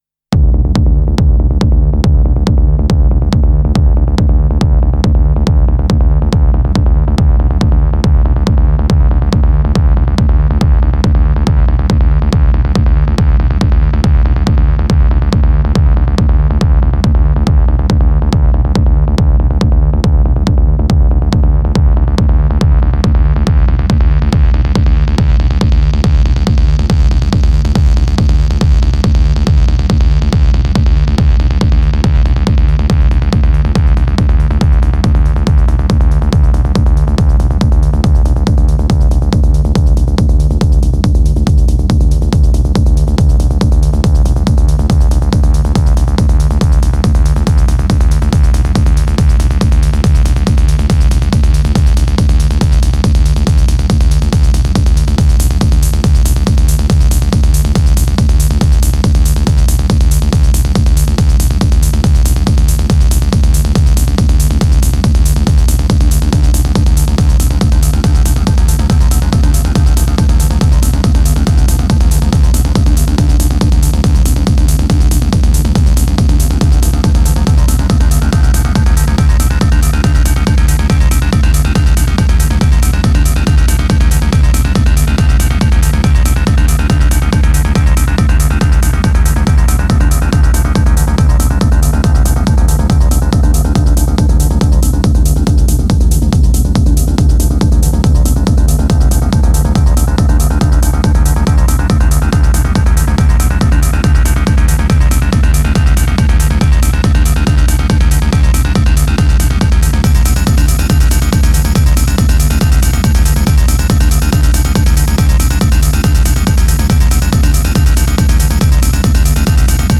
Strong straight forward techno time release!